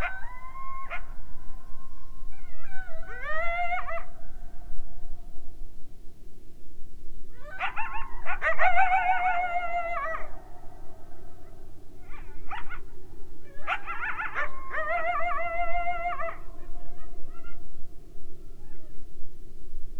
Coyotes recorded at Grand Teton National Park